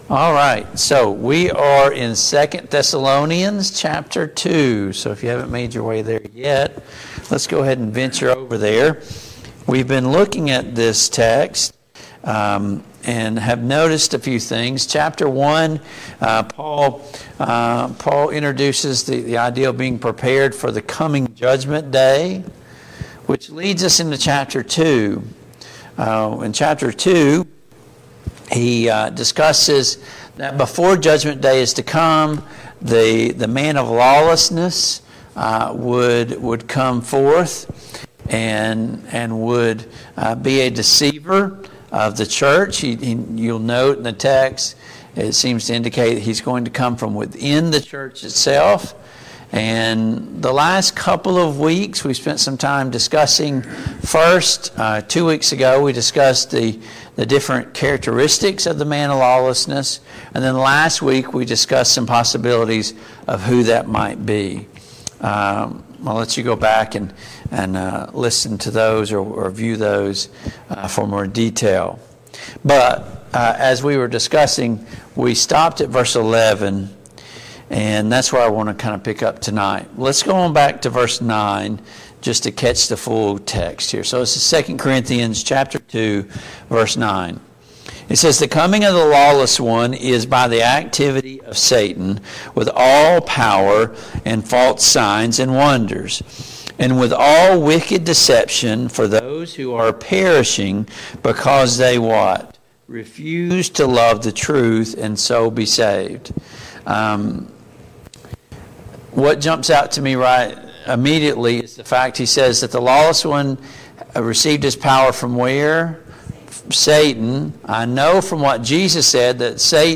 Passage: 2 Thessalonians 2:9-17 Service Type: Mid-Week Bible Study